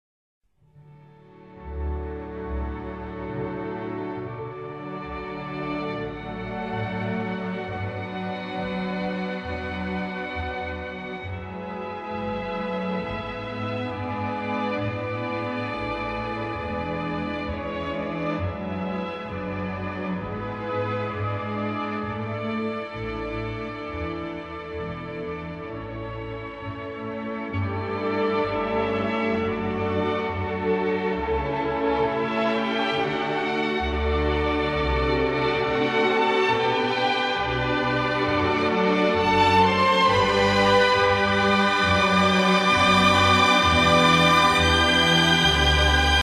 Orchesterfassung / Orchestra Version
Orchesterfassung